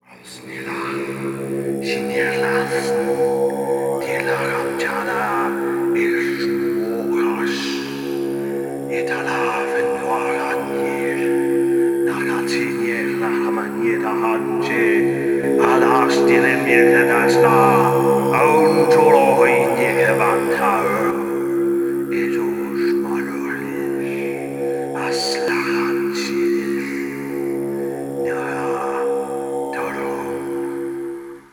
“Summoning Demonic Host” Created in Sound Booth CS4 By
summonig_demonic_host2.wav